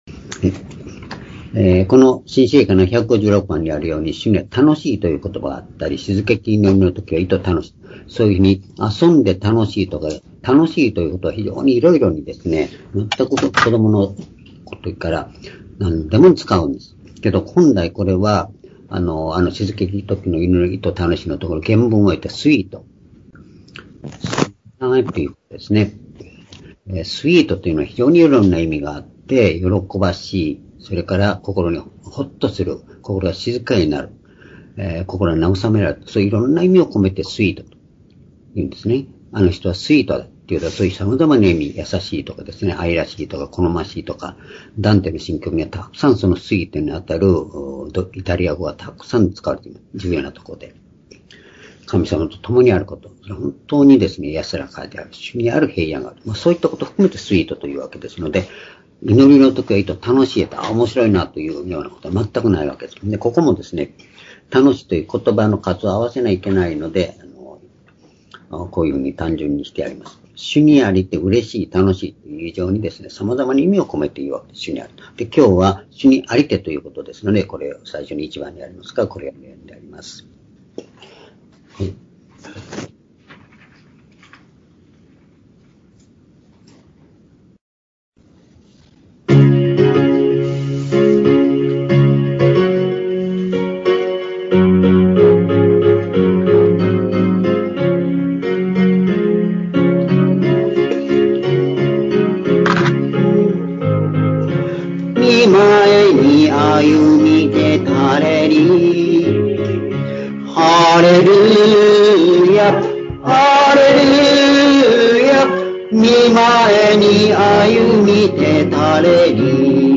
「主にありて堅く立ち、主にあって常に喜べ」フィリピ書4章1節～4節-2020年11月1日（主日礼拝）